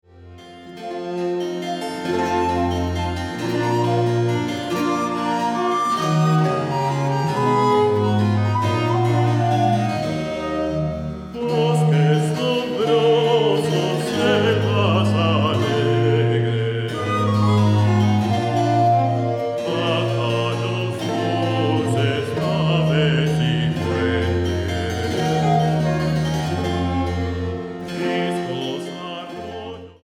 flautas de pico